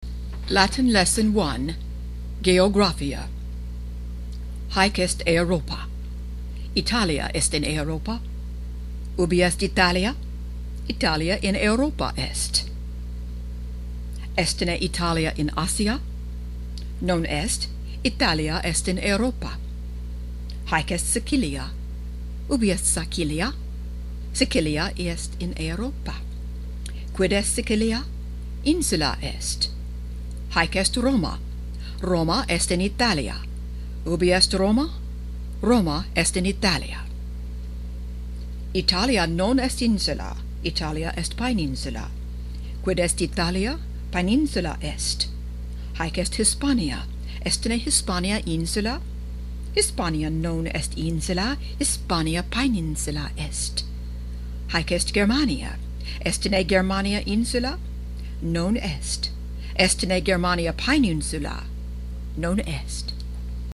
Latin Lesson 1